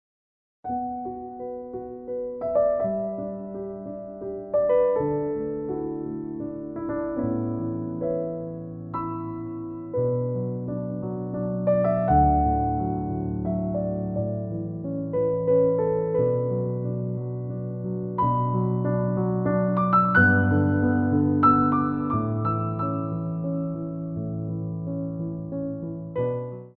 Piano Arrangements of Popular Music
3/4 (8x8)